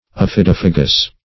Search Result for " aphidophagous" : The Collaborative International Dictionary of English v.0.48: Aphidophagous \Aph`i*doph"a*gous\, a. [Aphis + Gr.
aphidophagous.mp3